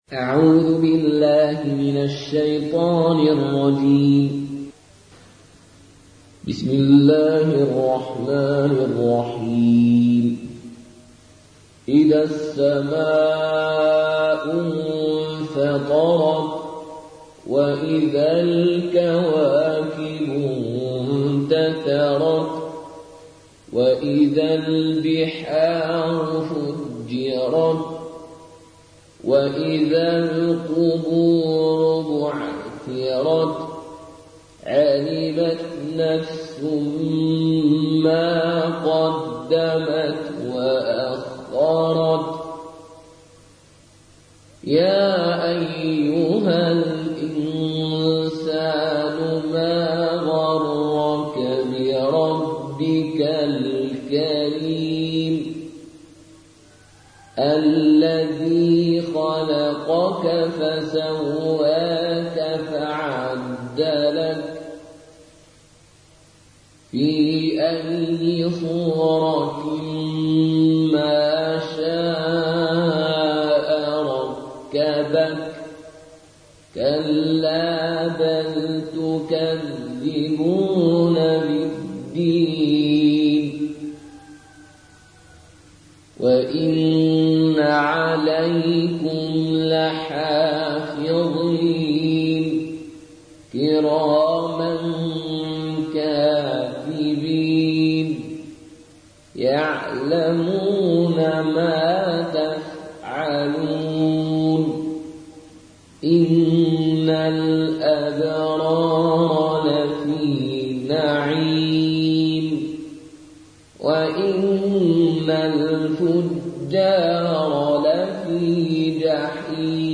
Qaloon an Nafi